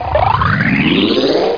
fallschirm2.mp3